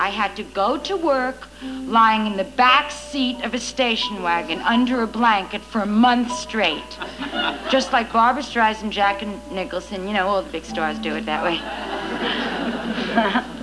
In addition, I am pleased to be able to include several files recorded from the rarely seen episode of "Saturday Night Live" which Louise Lasser hosted on July 24, 1976.
The following are from her second monologue.